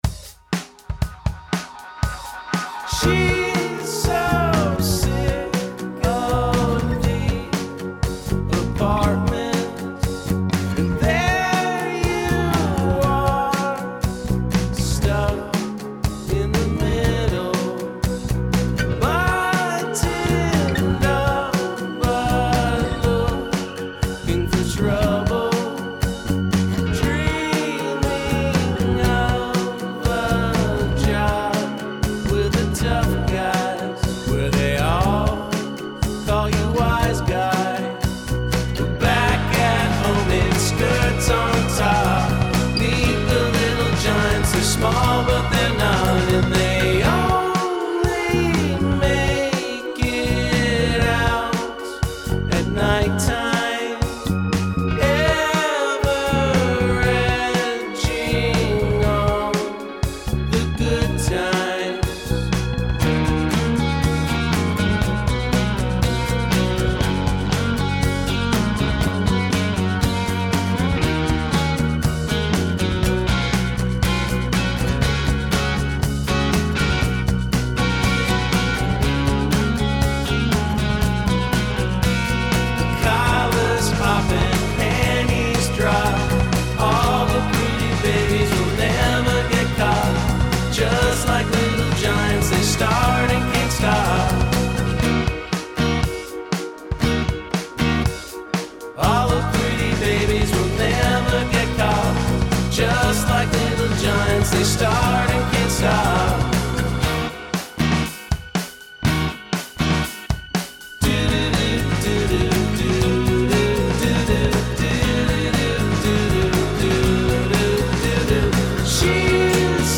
power-pop